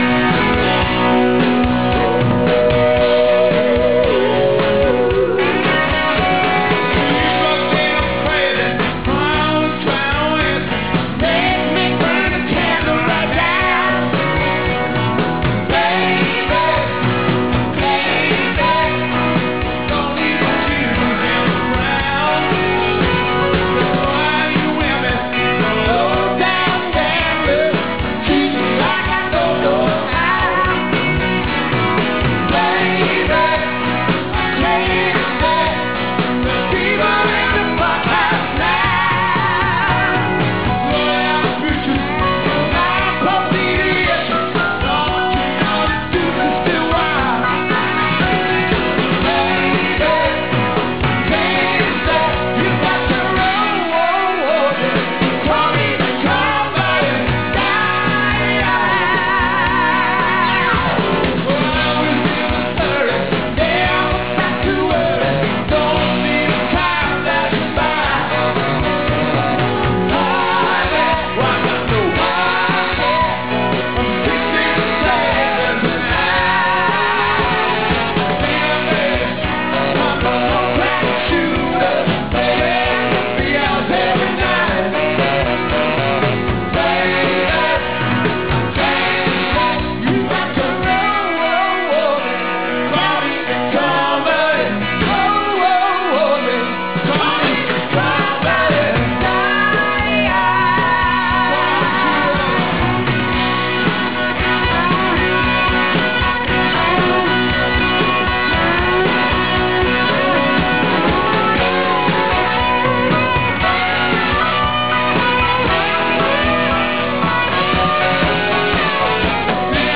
Live Audio Clips
Foxboro Sep. 5th